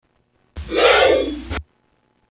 Cobra
Tags: Science & Nature Animals Egypt Animals of Egypt Ancient Egypt